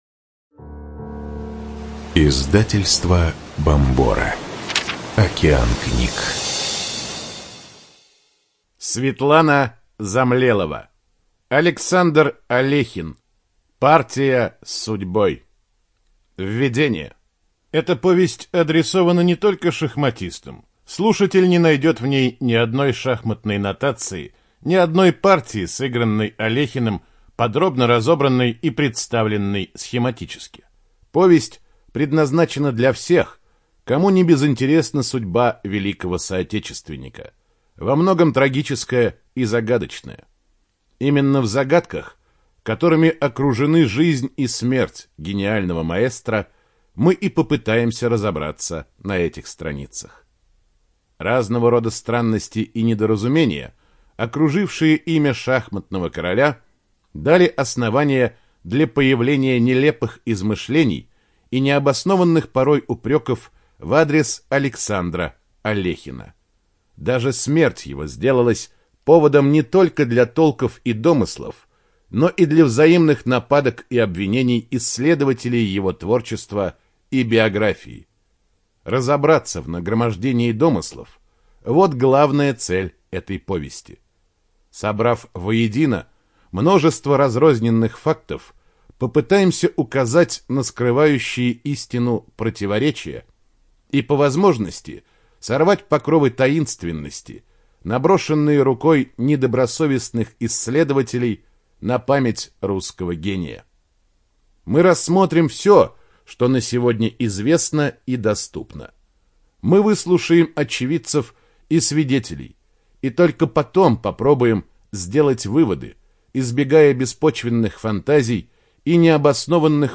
Студия звукозаписиБомбора